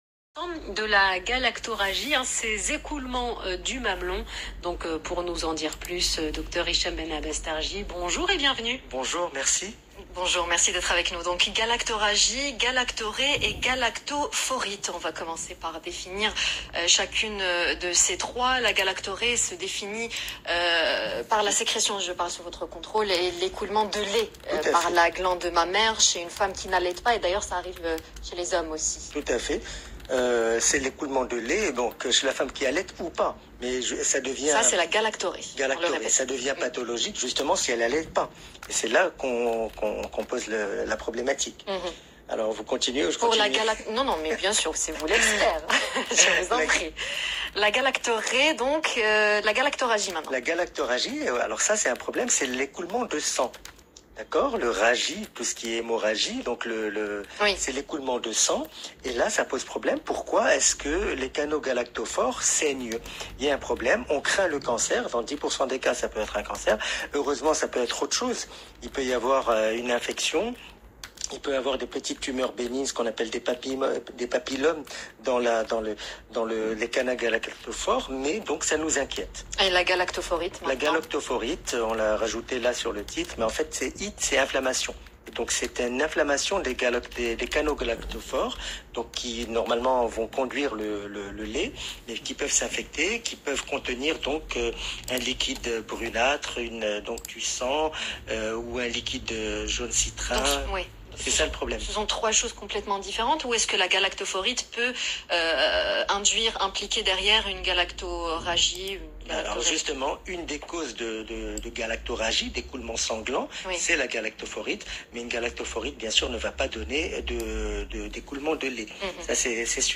Plus d’explications dans cette interview de l’Heure Essentielle sur Luxe Radio du 1er février 2022